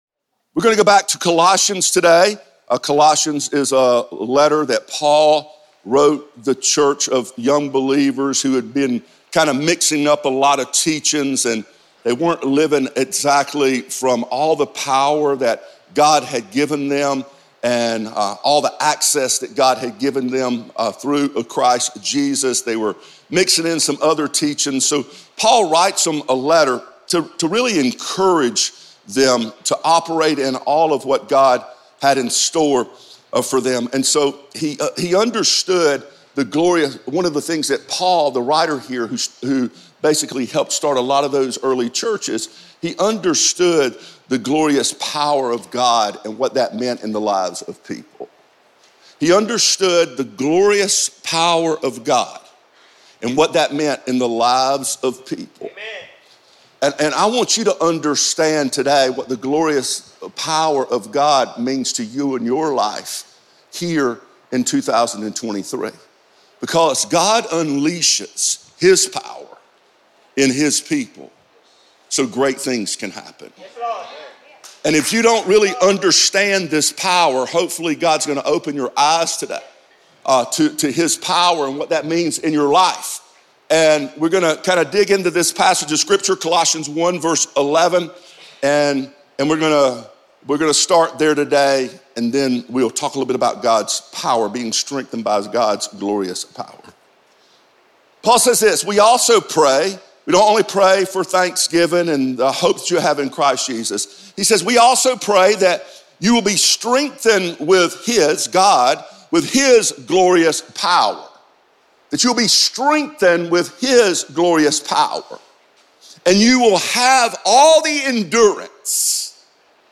He is known for his ability to captivate and challenge the audience by delivering the Word of God in a way that is relevant to our world today.